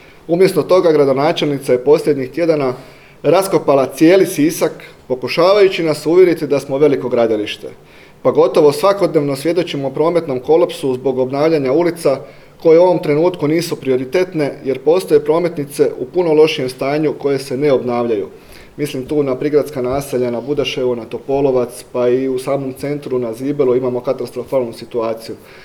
na danas održanoj tiskovnoj konferenciji